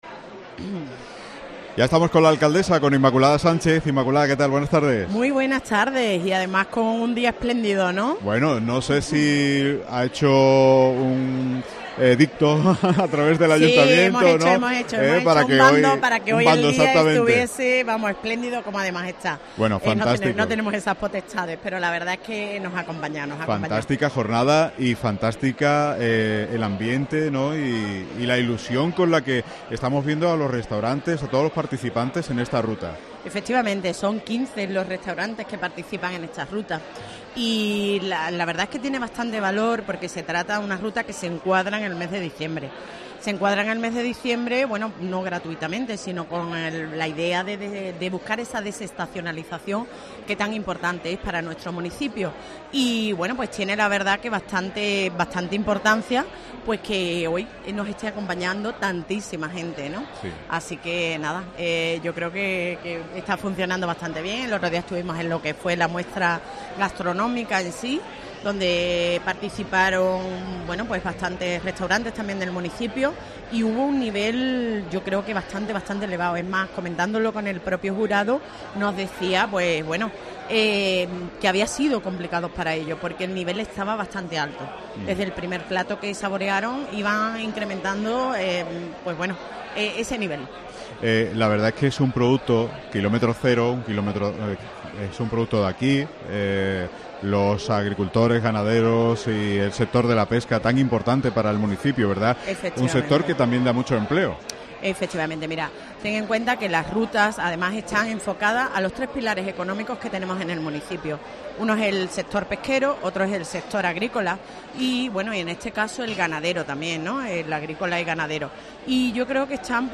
Inmaculada Sánchez, Alcaldesa de Conil - Inauguración Ruta Gastronómica del Retinto